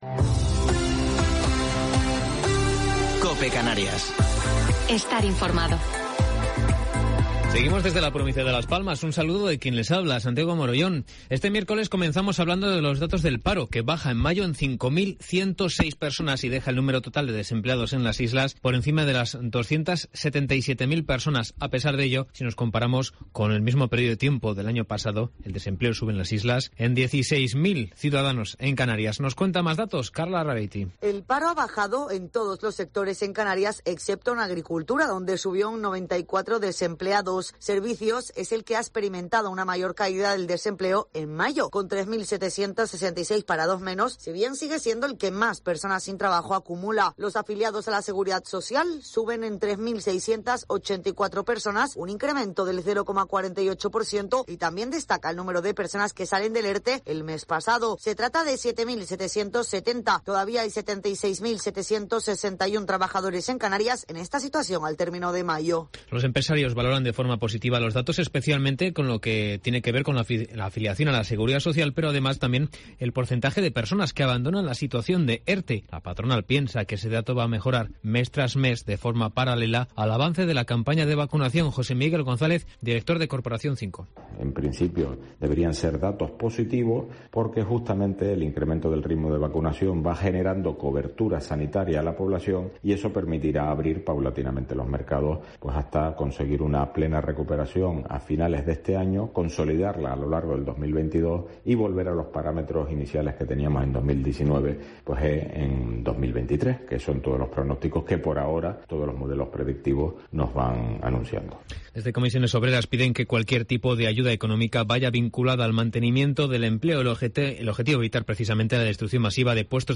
Informativo local 2 de Junio del 2021